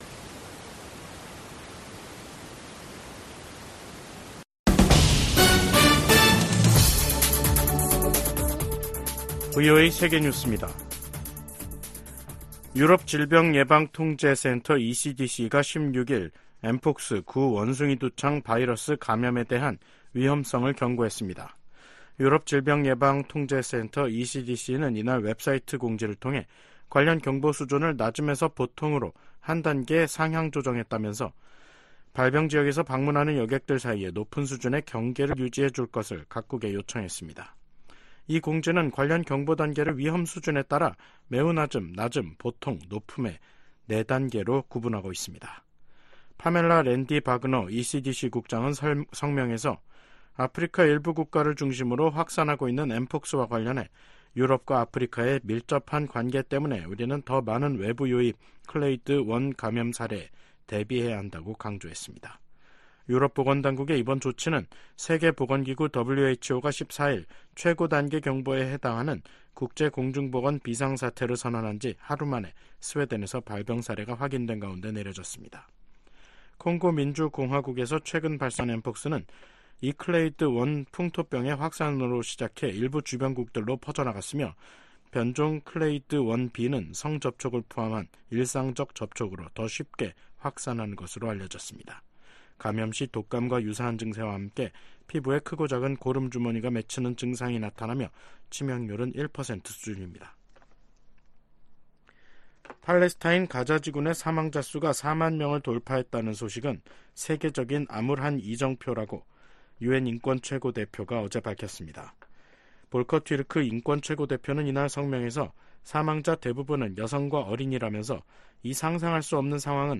VOA 한국어 간판 뉴스 프로그램 '뉴스 투데이', 2024년 8월 16일 3부 방송입니다. 미국 정부가 일본 고위 당국자들의 야스쿠니 신사 참배를 “과거 지향적”이라고 평가했습니다. 백악관 당국자가 연내 미한일 3국 정상회담 개최 가능성을 거론했습니다. 윤석열 한국 대통령이 발표한 자유에 기반한 남북 통일 구상과 전략에 대해 미국 전문가들은 “미래 비전 제시”라고 평가했습니다.